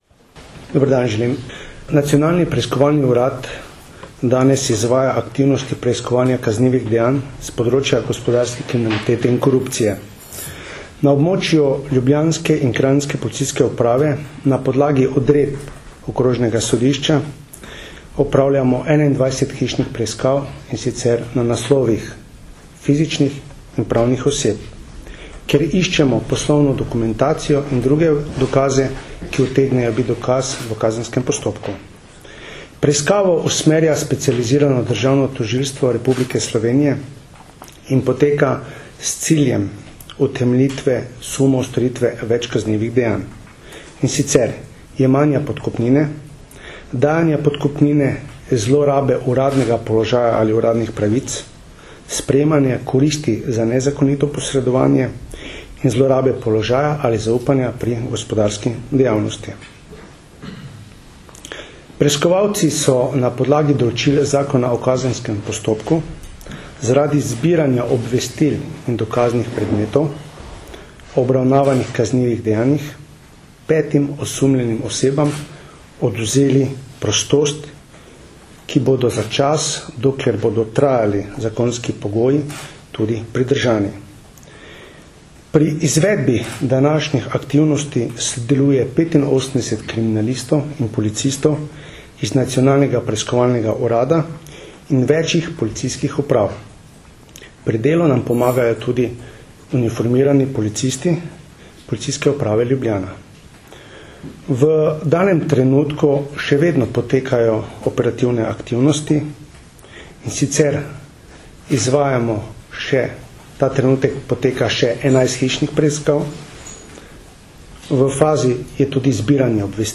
Zvočni posnetek izjave